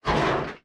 foundry-slide-close.ogg